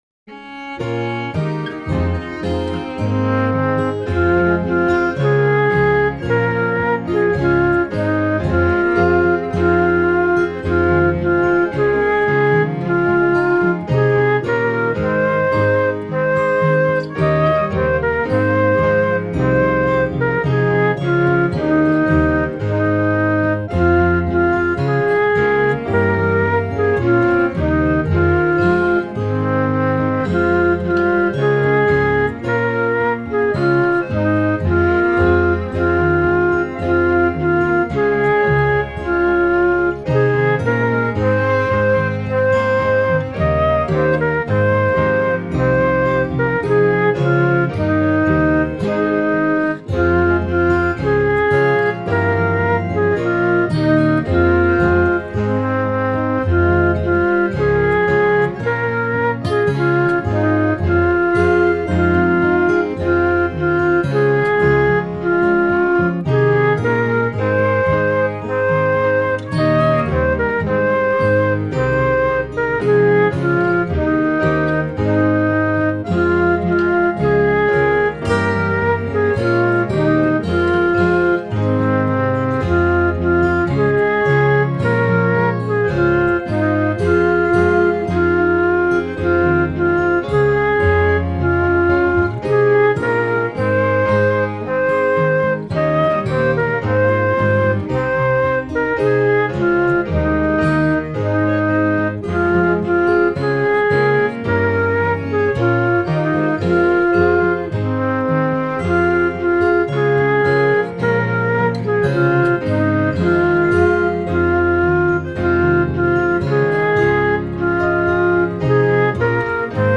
A song for Good Friday or Lent
It does go quite high.